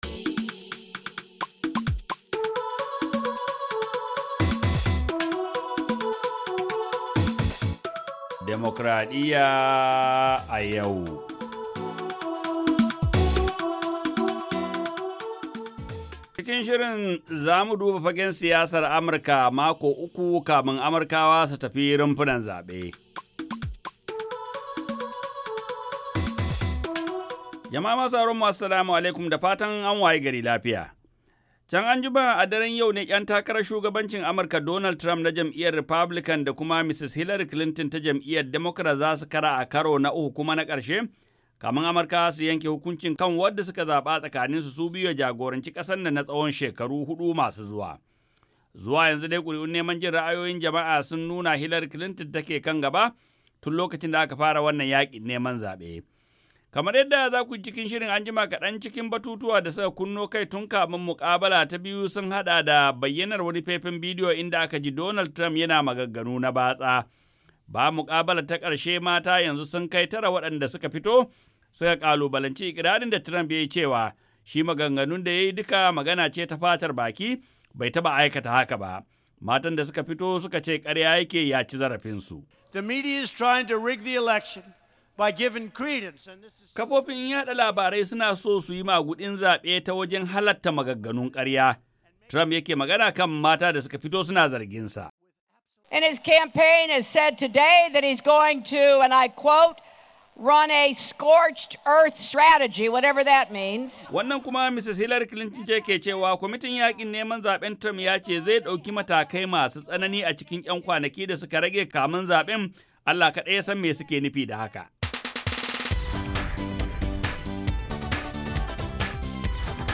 Ga cikakken rahoton